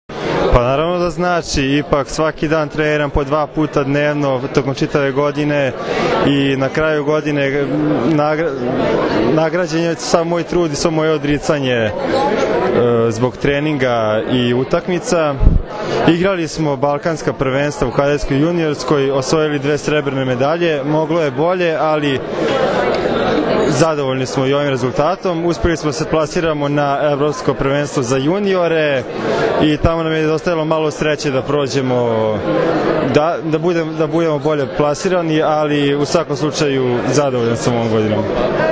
Odbojkaški savez Srbije je danas u beogradskom hotelu “Metropol Palas” organizovao Novogdišnji koktel “Naša strana mreže”, na kojem su dodeljeni Trofeji “Odbojka spaja”, “Budućnost pripada njima”, Trofeji za najbolju odbojkašicu i odbojkaša i najbolju odbojkašicu i odbojkaša na pesku, kao i prvi put “specijalna plaketa OSS”.
IZJAVA